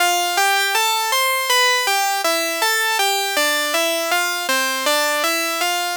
Track 16 - E-Harpsichord 02.wav